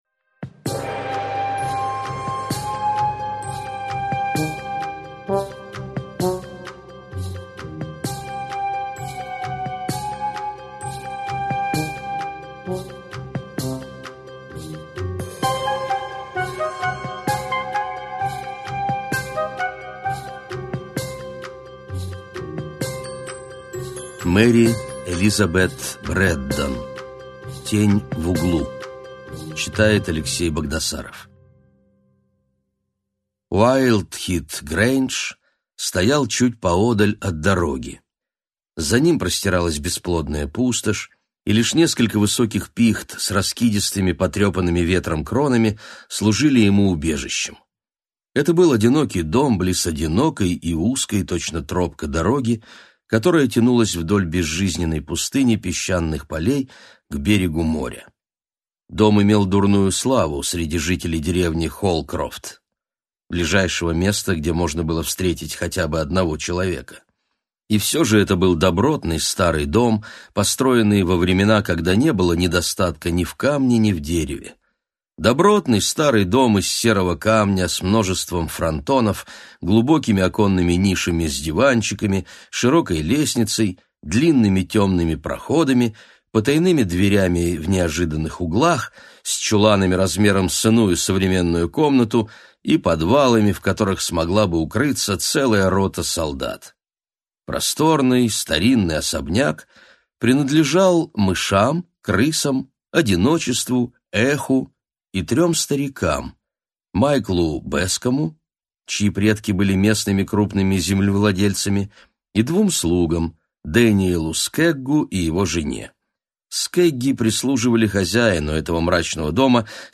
Аудиокнига Готические истории | Библиотека аудиокниг